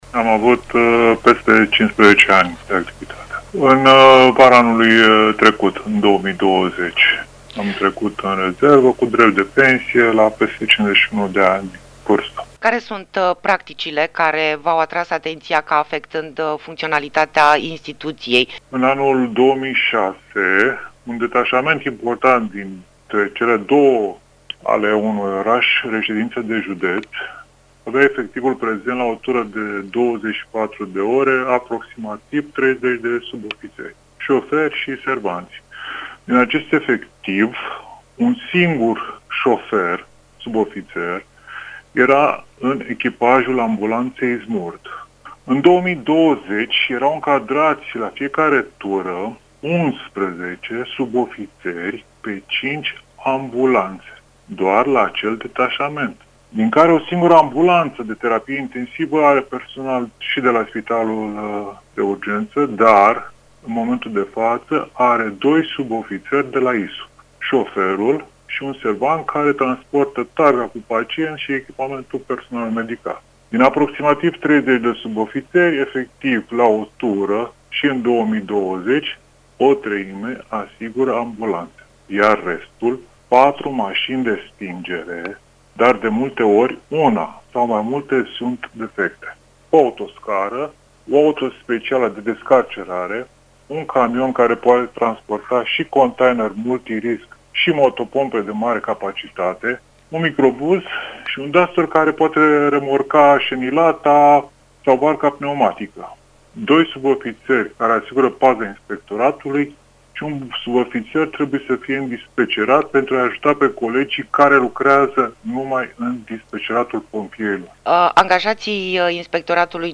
18-februarie-Interviu-probleme-ISU.mp3